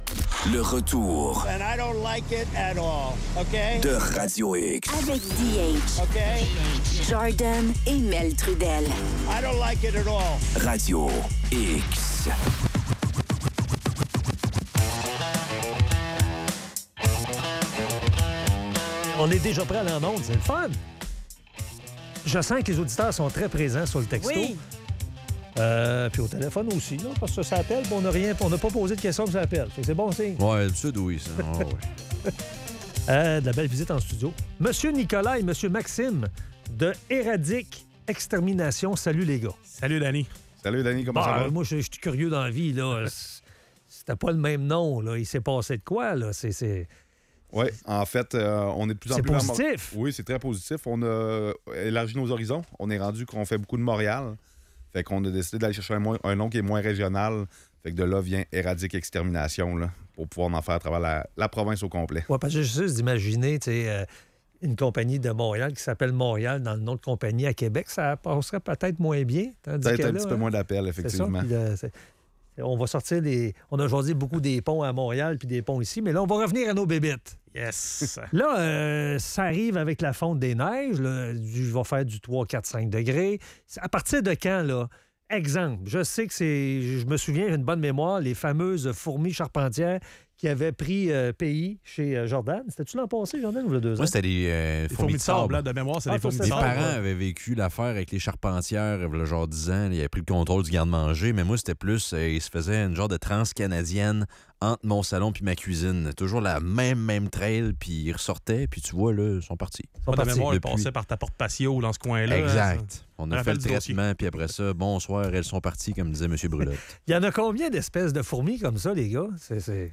Entrevue